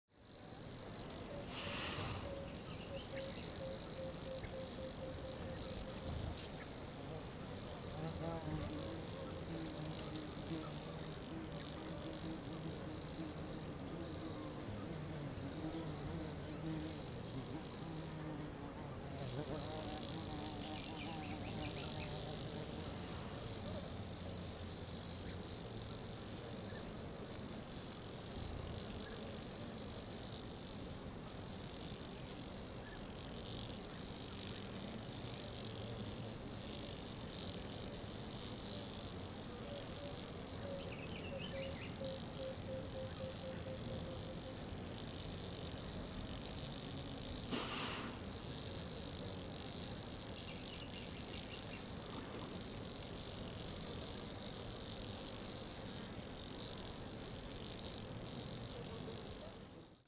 دانلود آهنگ رودخانه 2 از افکت صوتی طبیعت و محیط
دانلود صدای رودخانه 2 از ساعد نیوز با لینک مستقیم و کیفیت بالا
جلوه های صوتی